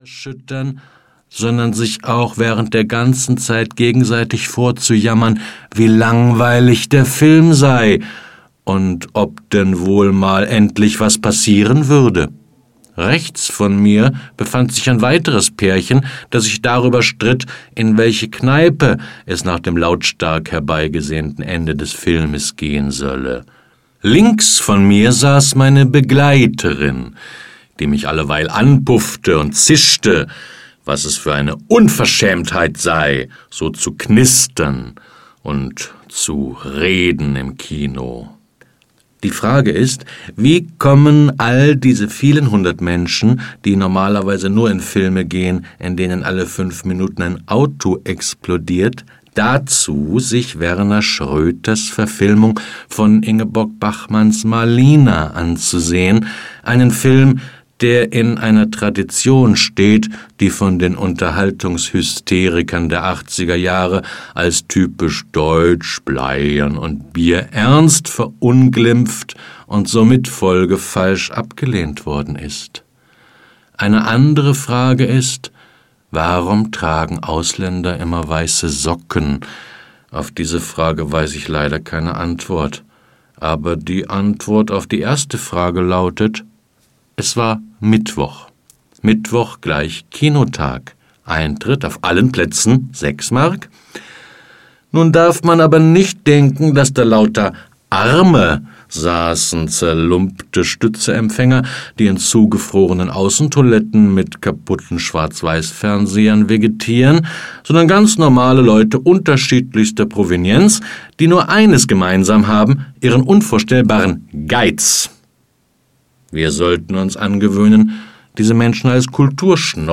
Für Nächte am offenen Fenster - Folge zwei - Max Goldt - Hörbuch